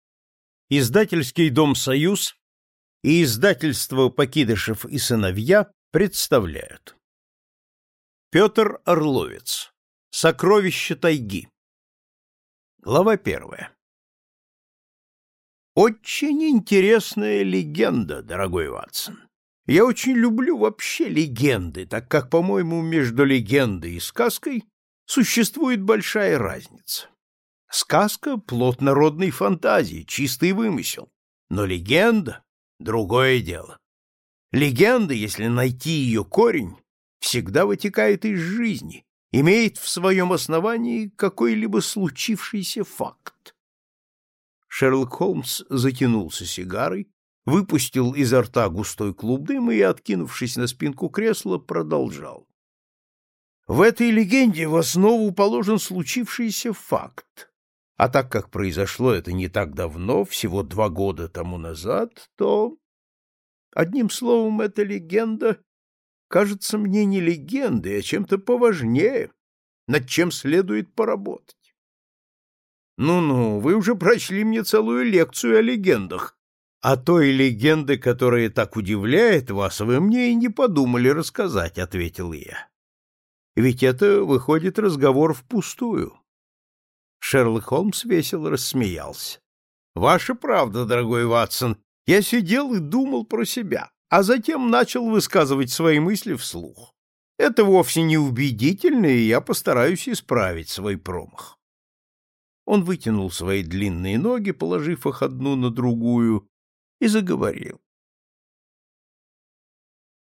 Аудиокнига Шерлок Холмс в Сибири | Библиотека аудиокниг